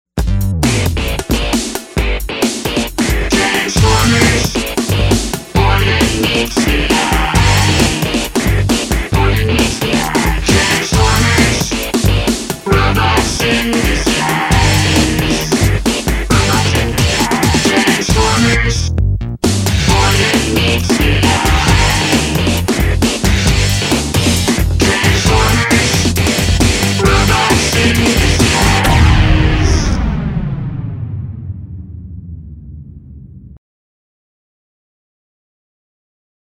Canción de la secuencia de título